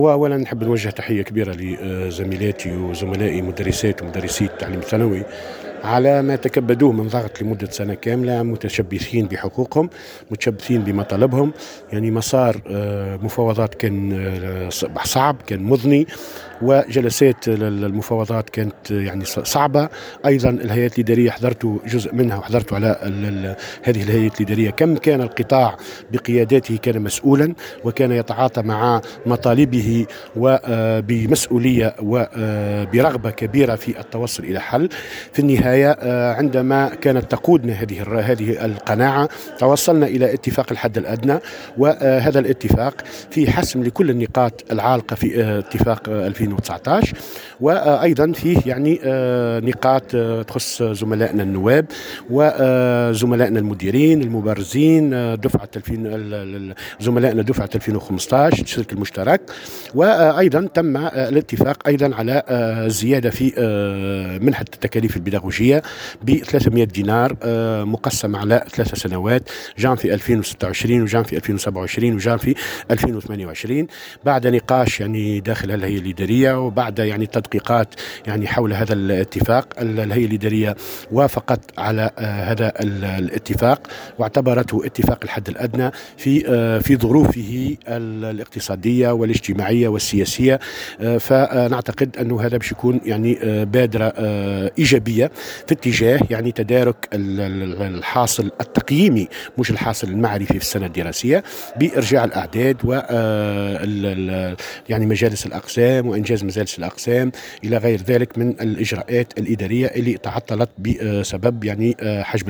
(تصريح)